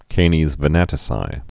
(kānēz vĭ-nătĭ-sī)